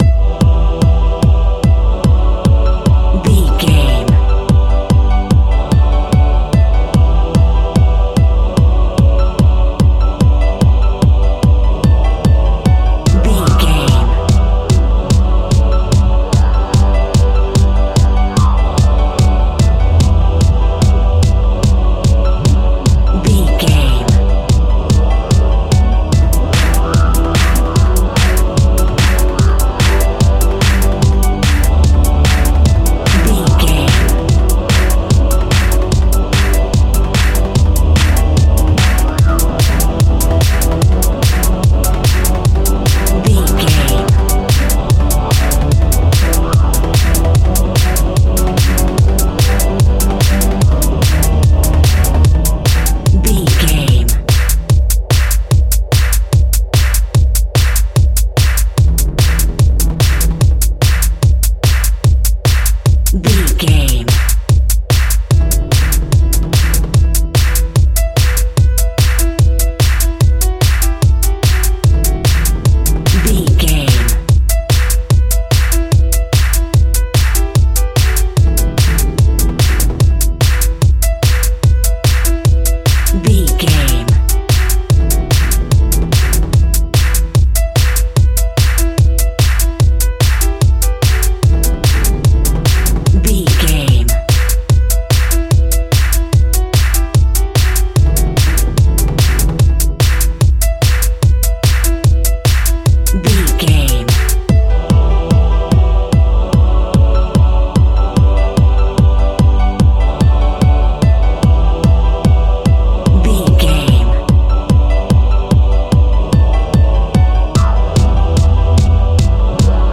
Aeolian/Minor
Fast
futuristic
hypnotic
epic
dark
drum machine
synthesiser
electronic
uptempo